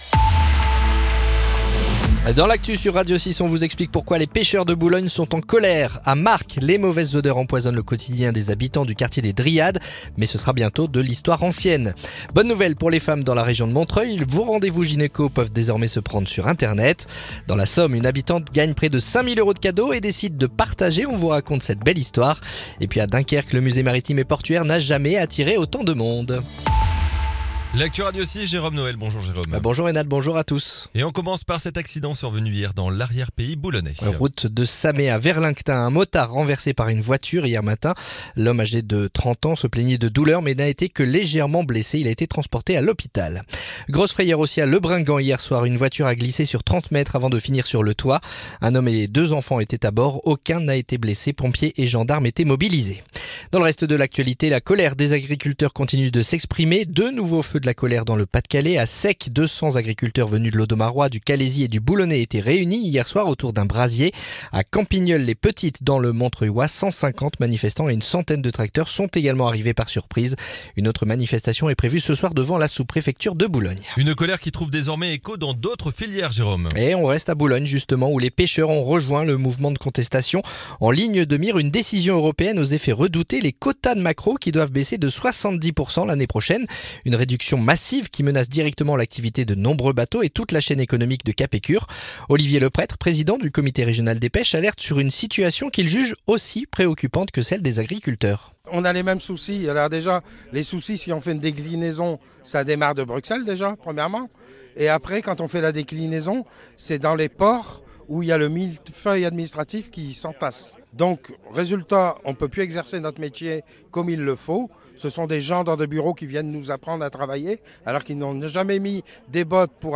Le journal du mardi 23 décembre